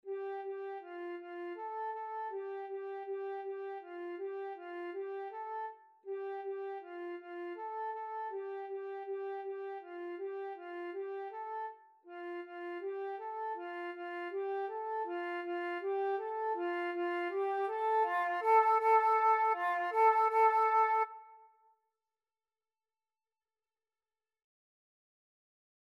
2/4 (View more 2/4 Music)
F5-A5
Beginners Level: Recommended for Beginners
Flute  (View more Beginners Flute Music)
Classical (View more Classical Flute Music)